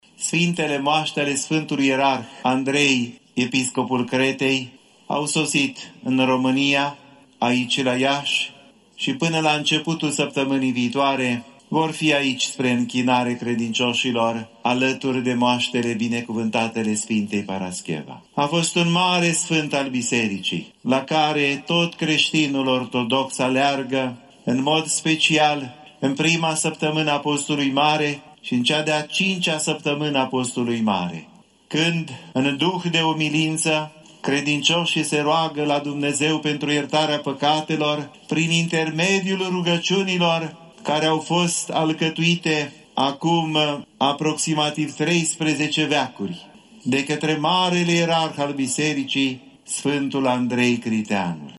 Moaștele Sfântului ierarh Andrei Criteanul, episcopul Cretei, au fost aduse, aseară, la Catedrala Mitropolitană din Iași, în prezența a mii de pelerini.
În cuvântul de învățătură rostit cu acest prilej, Înaltpreasfințitul Teofan, Mitropolitul Moldovei și Bucovinei, a subliniat importanța rugăciunilor de pocăință, rostite în prima și în a cincea săptămână a Postului Mare, rugăciuni scrise cu evlavie de Sfântul Andrei Criteanul: Sfintele Moaște ale Sfântului Ierarh Andrei Criteanul, episcopul Cretei, au sosit în România, aici la Iași și până la începutul săptămânii viitoare vor fi aici spre închinarea credincioșilor, alături de moaștele binecuvântatele Sfintei Parascheva.